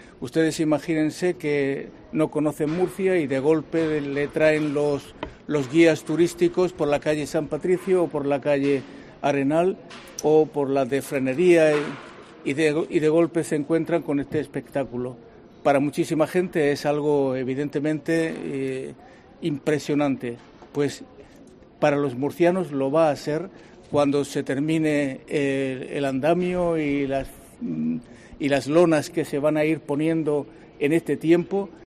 Obispo Diocesis de Cartagena, Monseñor Lorca Planes